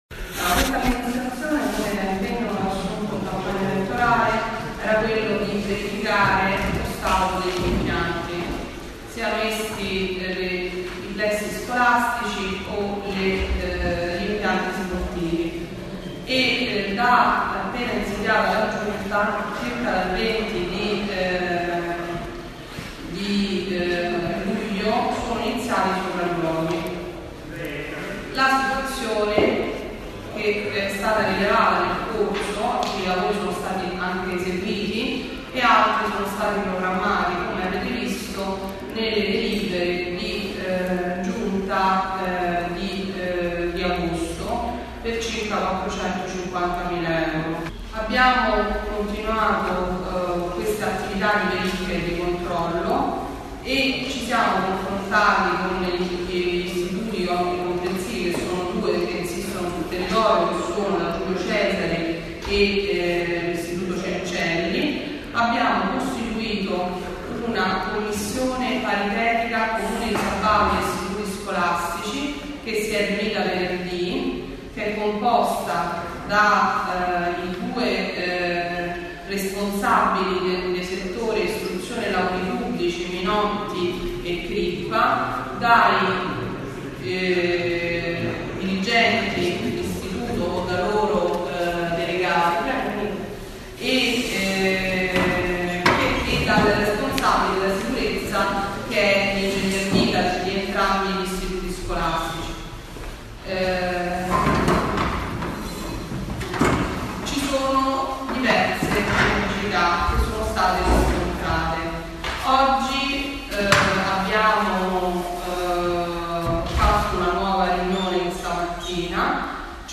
Oggi, 11 Settembre, vi è stata presso il palazzo comunale, una conferenza stampa per esplicare i problemi riguardanti l’apertura delle scuole, e dei lavori che l’amministrazione comunale sta svolgendo sulle strutture scolastiche.
conferenza_scuole.mp3